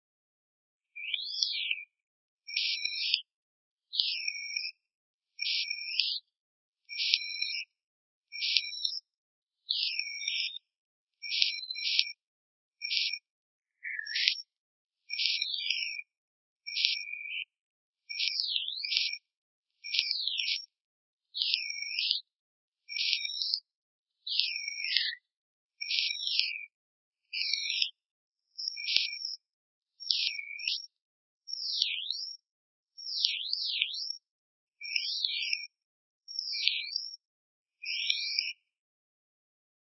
On peut essayer avec un alphabet et une police avec serif, cette fois on utilisera le flag -l afin d’obtenir une échelle de fréquence linéaire.
Fichier OGG Alphabet retrouvé depuis le fichier son
Avec un peu d’entraînement, on distingue plutôt bien les différentes lettres les unes des autres.